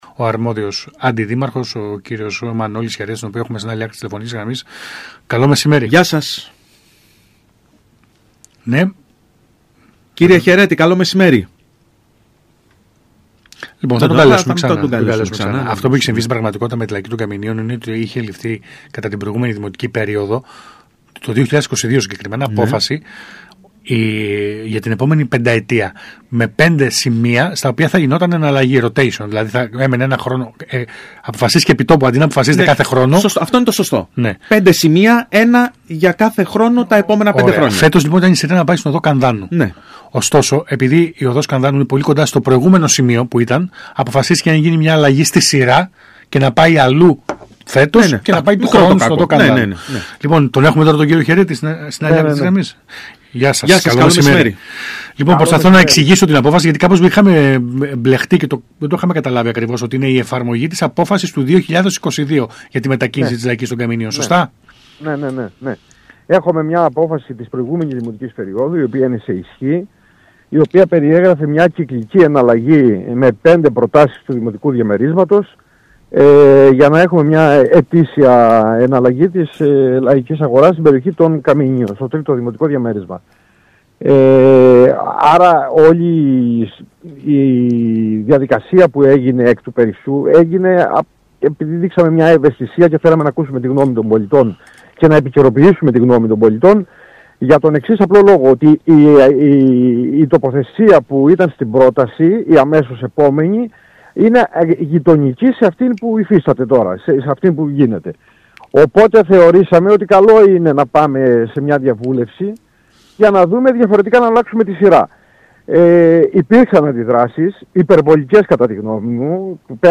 Ακούστε εδώ όσα είπε στον ΣΚΑΙ Κρήτης 92.1 ο Αντιδήμαρχος Δημοτικής Αστυνομίας και Πολιτικής Προστασίας Μανώλης Χαιρέτης: